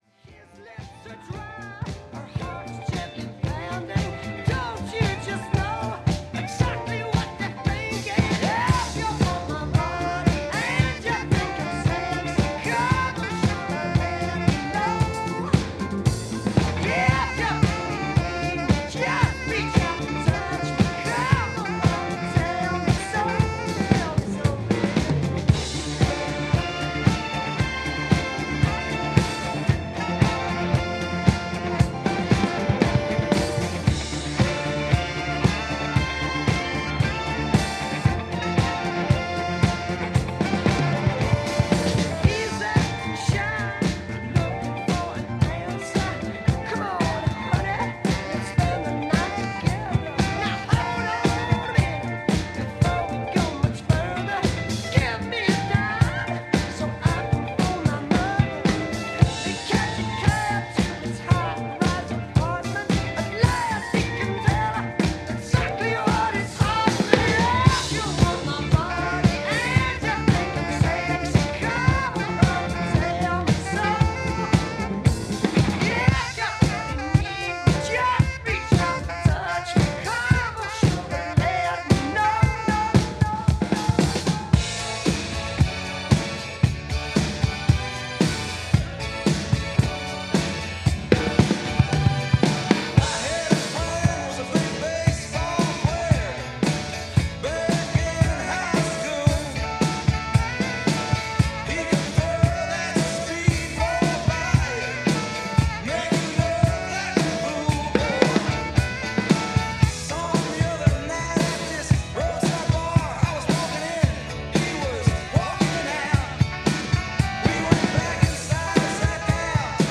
A live wedding mix